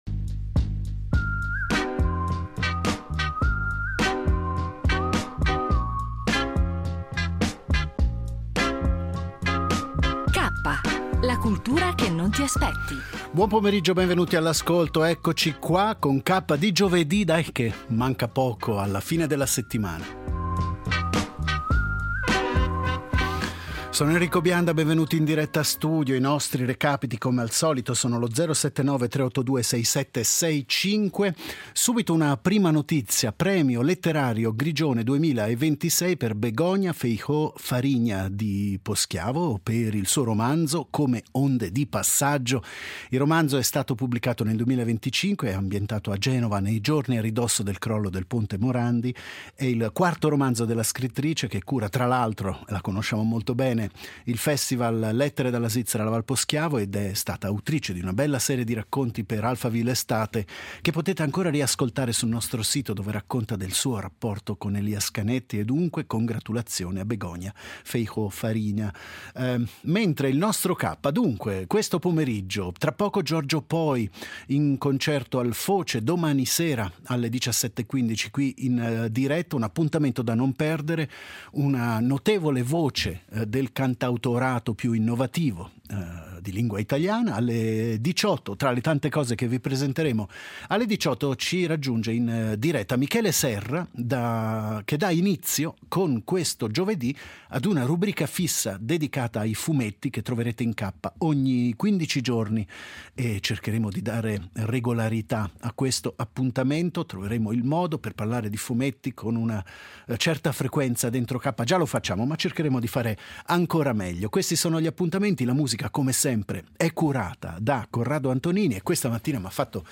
La trasmissione ospita anche Giorgio Poi , che presenta il suo quarto album, “ Schegge ”, uscito il 2 maggio 2025. Michele Serra introduce una nuova rubrica quindicinale sui fumetti curata, e presenta “L’autobus incantato” di Majid Vita, un graphic novel ispirato a fatti reali sull’oppressione degli intellettuali in Iran.